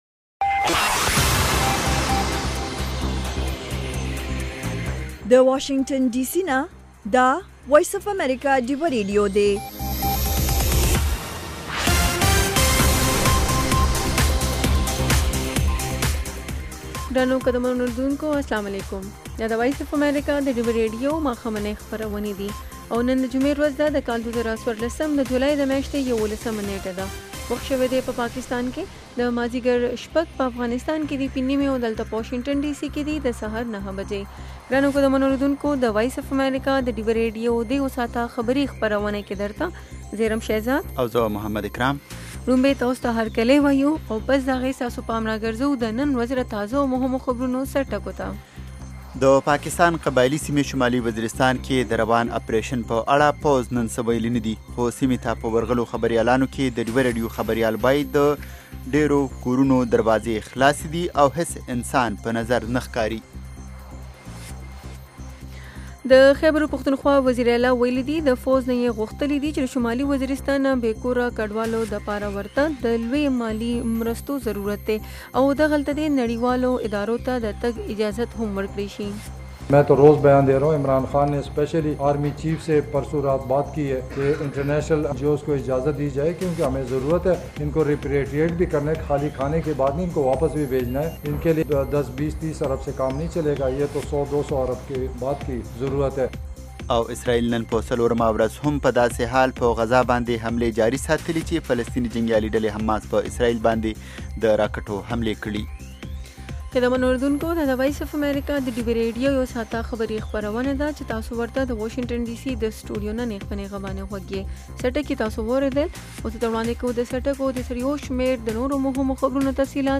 خبرونه - 1300